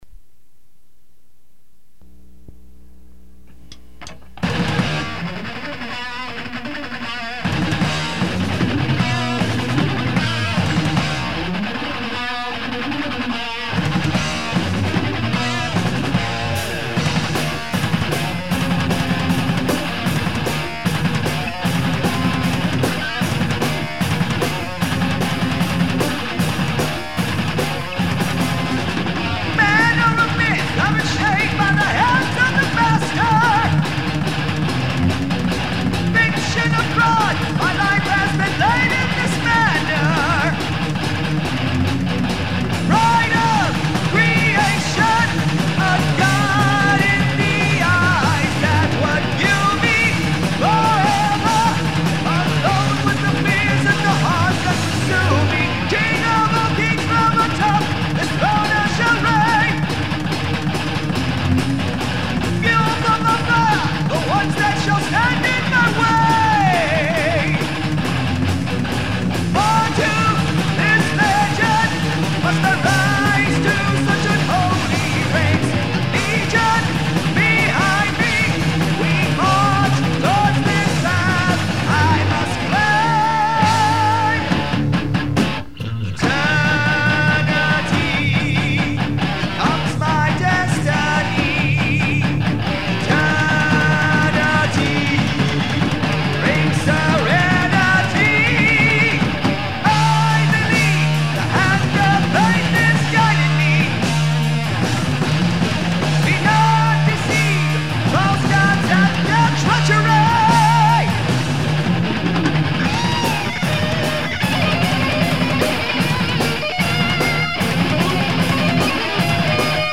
A fast, galloping verse and plenty of harmonies.